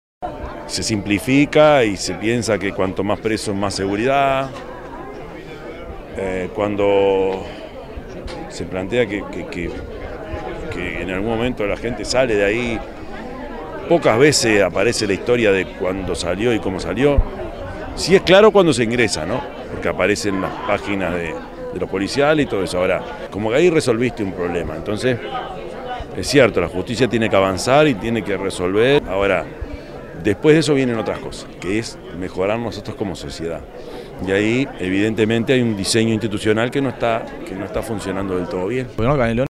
En marco del cierre de la Semana de la Convivencia, se realizó en Mercado Arenas de Carrasco (Municipio de Paso Carrasco) un conversatorio sobre la convivencia y rehabilitación como desafíos de Estado.
Durante el conversatorio, el Jefe de la comuna canaria, Prof. Yamandú Orsi, habló de los fundamentos filosóficos y antropológicos respecto a cómo solucionar la seguridad pública: "¿Qué es lo que queremos con las cárceles?" Reflexionó el mandatario.
intendente_de_canelones_prof._yamandu_orsi_7.mp3